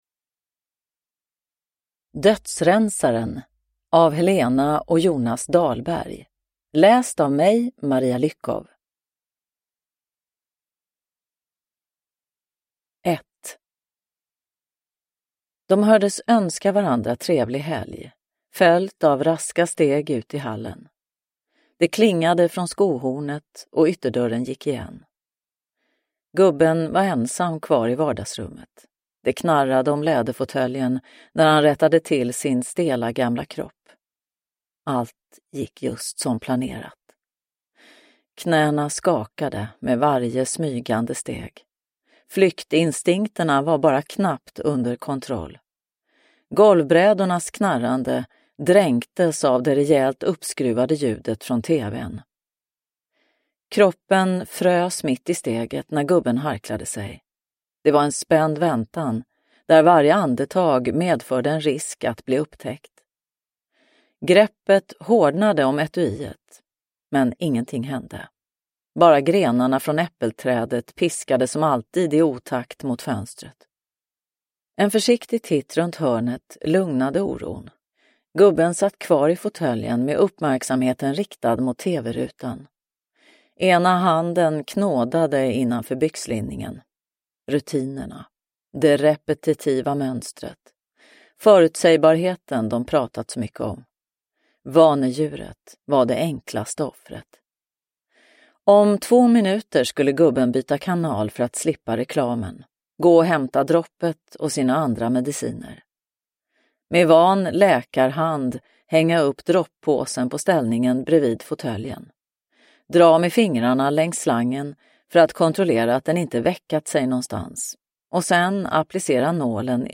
Dödsrensaren – Ljudbok – Laddas ner